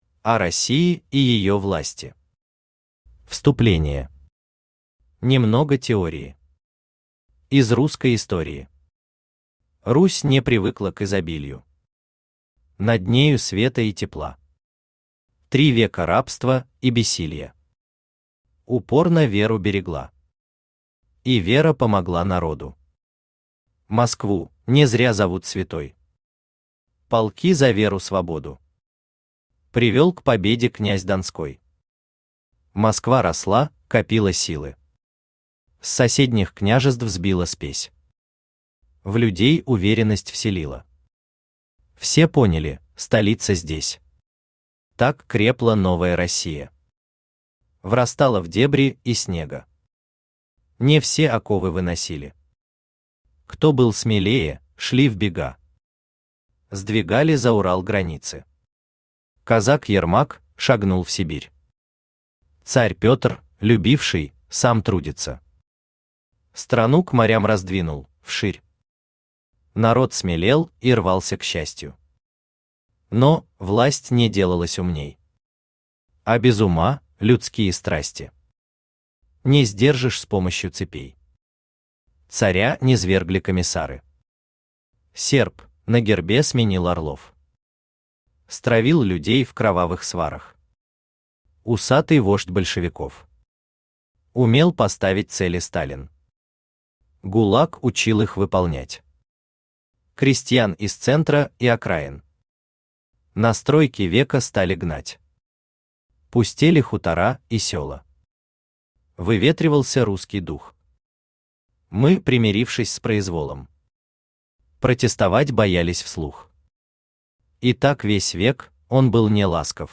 Аудиокнига О России и её власти | Библиотека аудиокниг
Aудиокнига О России и её власти Автор Николай Николаевич Самойлов Читает аудиокнигу Авточтец ЛитРес.